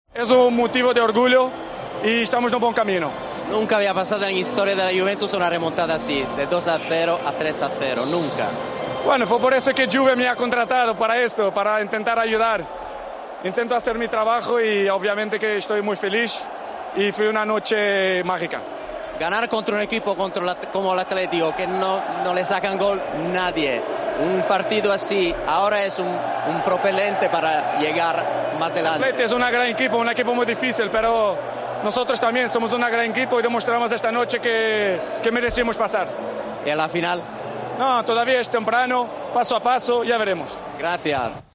"Es una noche especial. Ésta es la mentalidad Champions", comentó el goleador de la Juventus, en Sky Sport.